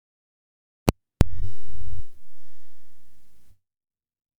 Strange Buzz noise after hitting record button
When I press the record button sometimes appears a strange buzz noise, this noise only happends the first 3-4 secods of a recording. Im using a Headset microphone enhanced with steelseries sonar. Heres a example of the noise